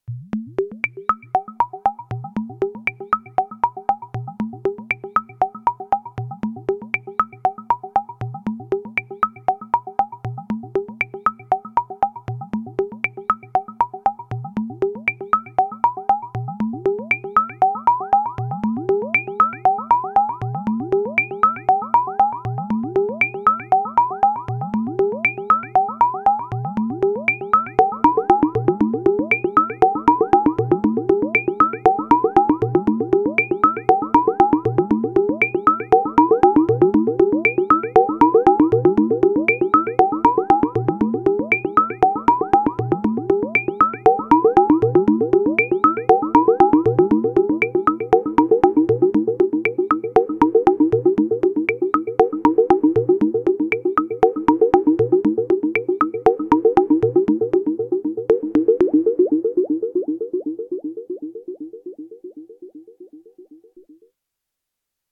AR_filter tracking from space